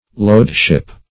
Lode-ship \Lode"-ship`\, n. An old name for a pilot boat.